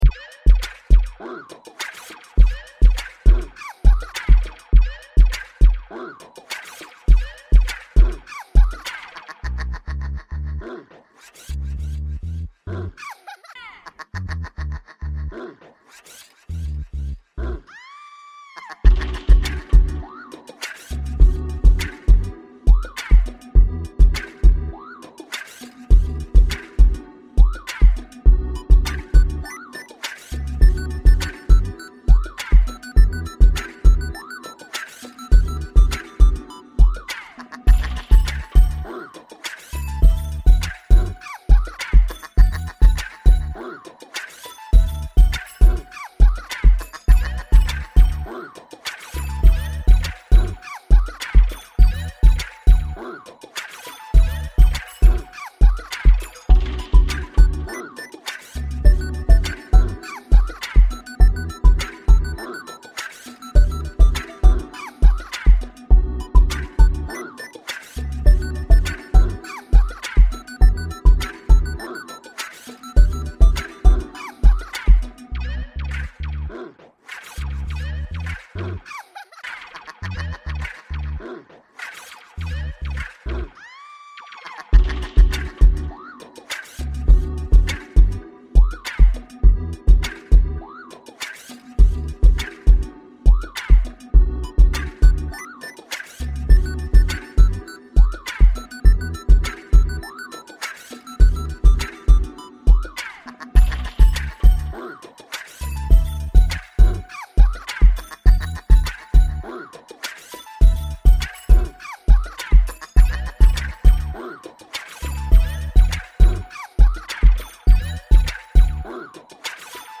very nice mellow groove and very creative use of varied sfx with the rhythm.  The piece was very musical -particularly the use of the electronic beeps.  Good use of laughs throughout.